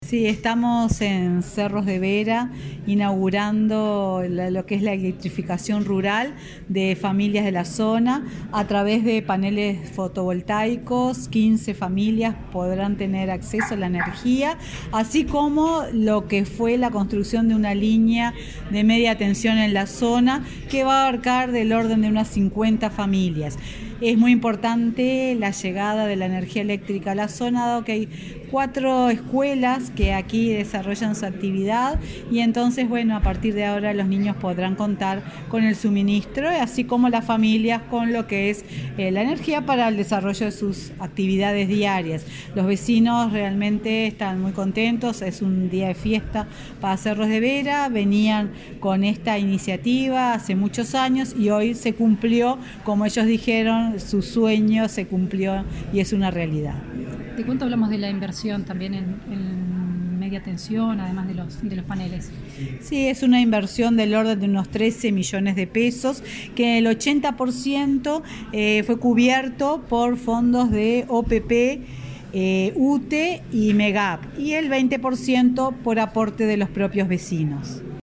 Declaraciones de la presidenta de UTE, Silvia Emaldi
El pasado viernes 27, la presidenta de UTE, Silvia Emaldi, inauguró una obra de electrificación rural en Cerros de Vera, en el departamento de Salto